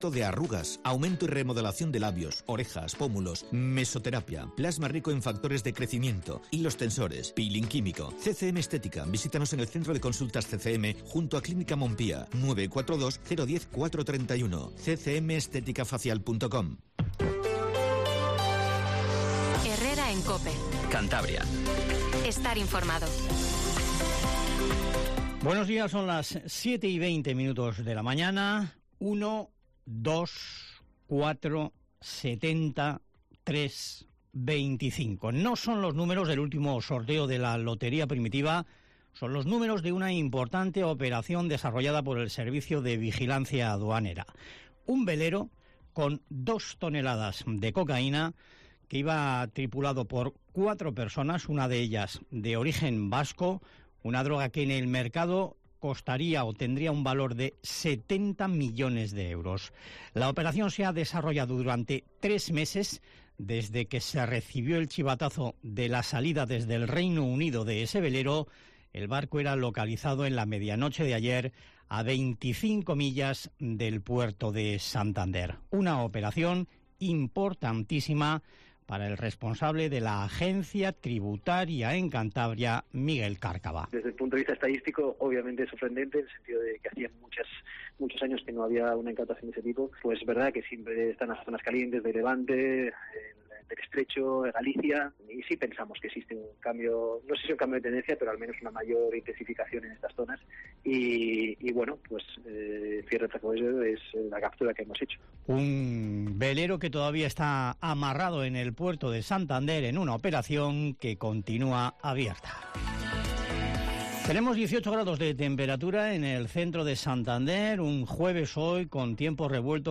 Informativo Matinal Cope 07:20